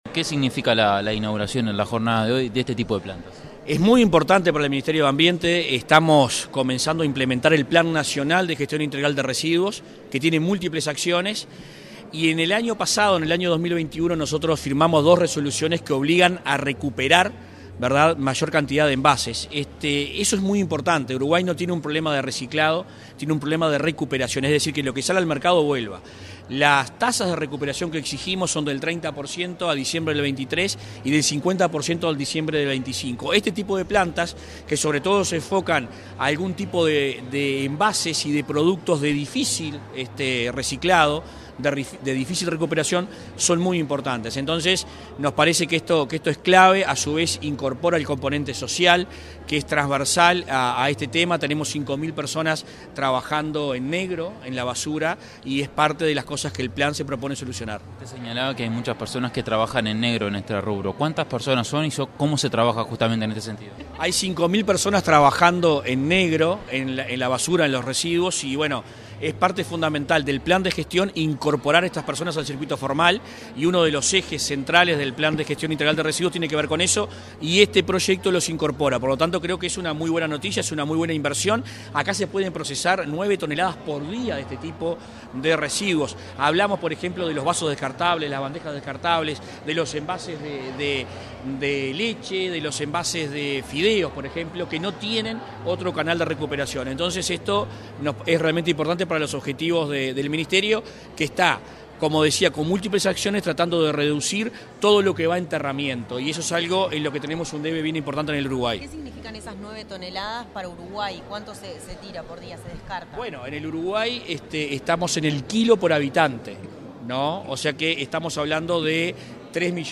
Palabras del ministro de Ambiente, Adrián Peña
Palabras del ministro de Ambiente, Adrián Peña 13/06/2022 Compartir Facebook X Copiar enlace WhatsApp LinkedIn Este 13 de junio, el ministro de Ambiente participó en la inauguración de una planta en la que se procesará 9 toneladas diarias de residuos y que empleará a participantes de los programas del Ministerio de Desarrollo Social (Mides).